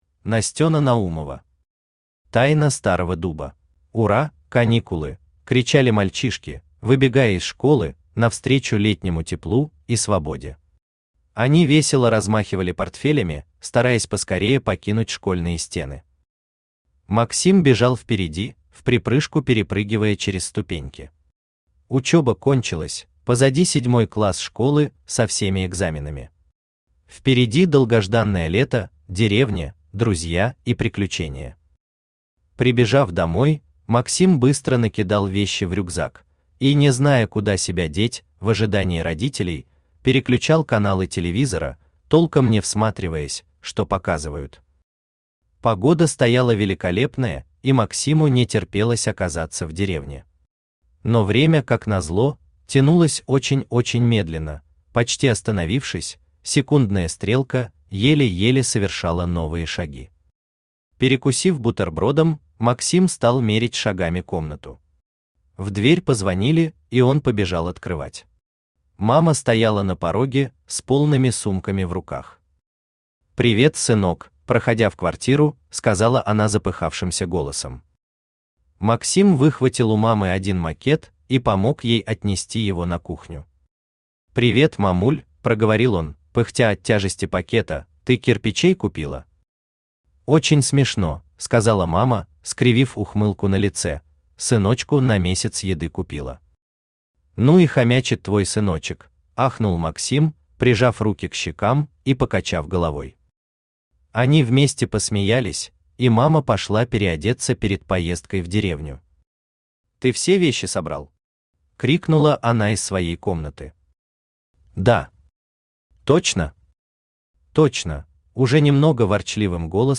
Аудиокнига Тайна старого дуба | Библиотека аудиокниг
Читает аудиокнигу Авточтец ЛитРес.